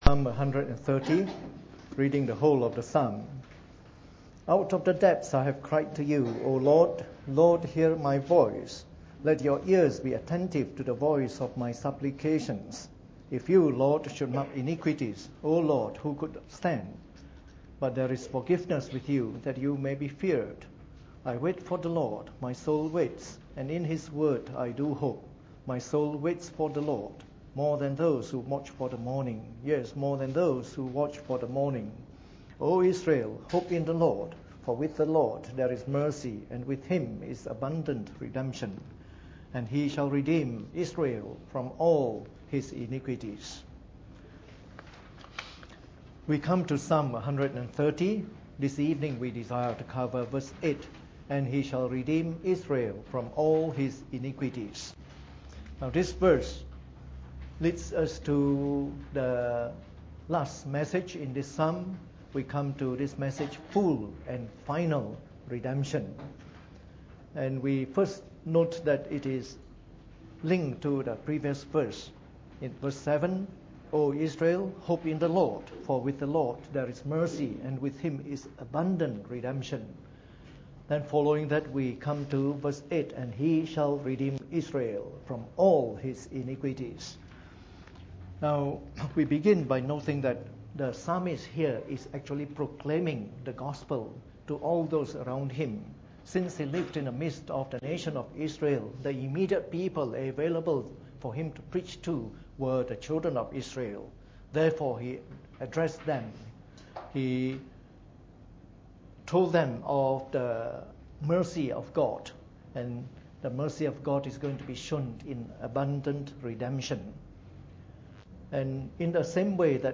Preached on the 25th of September 2013 during the Bible Study, the last talk in our series on Psalm 130.